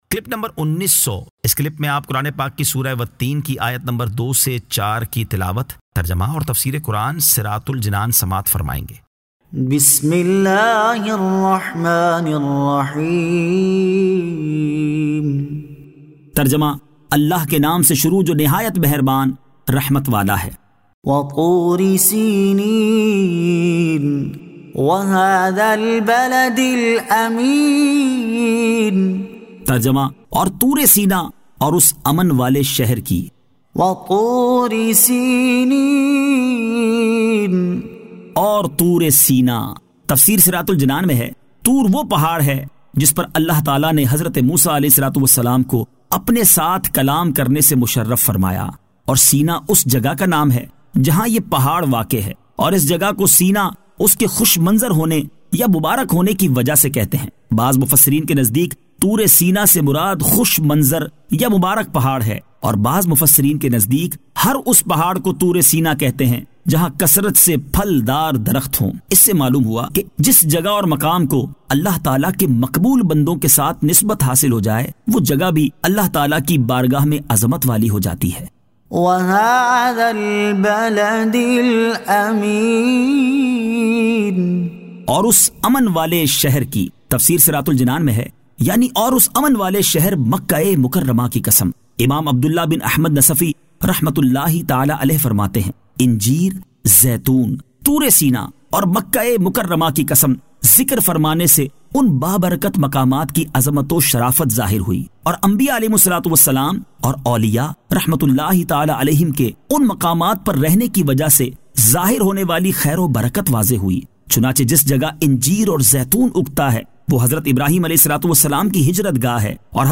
Surah At-Teen 02 To 04 Tilawat , Tarjama , Tafseer